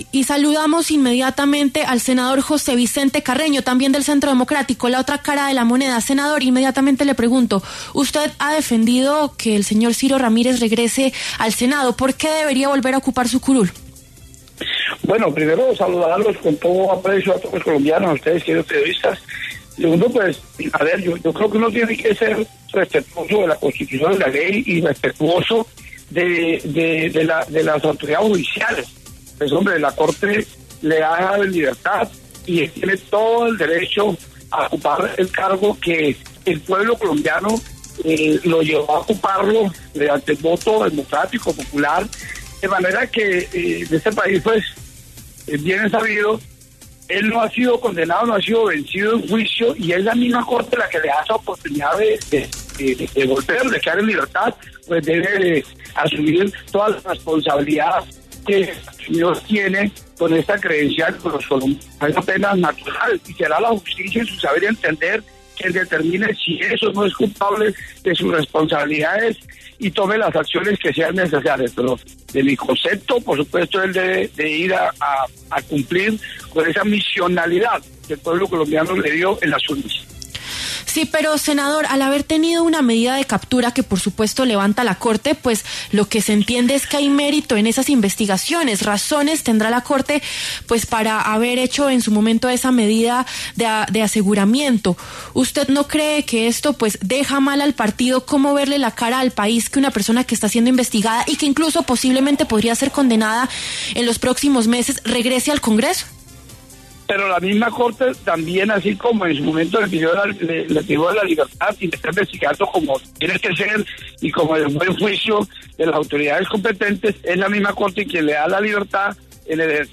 José Vicente Carreño, senador del Centro Democrático, habló en los micrófonos de W Fin De Semana sobre la posible libertad de Ciro Ramírez y, con ello, su ocupación nuevamente de una curul en el Senado.